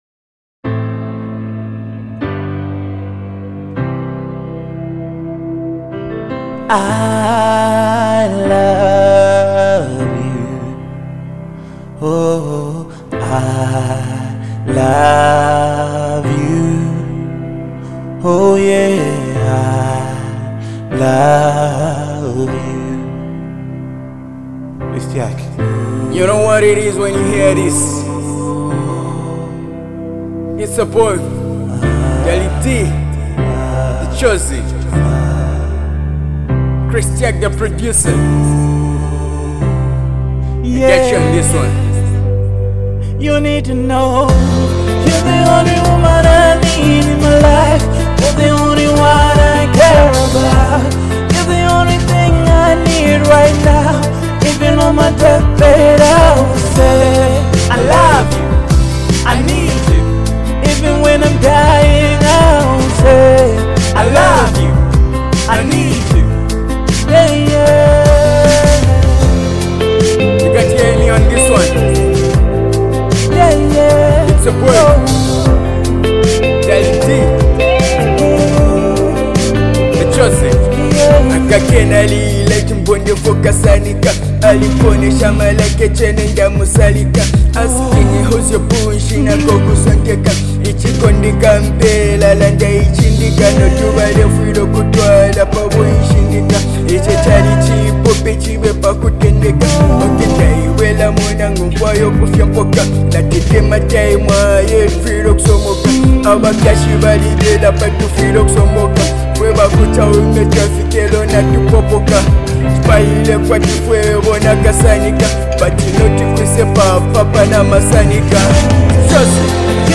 Genre: Emotional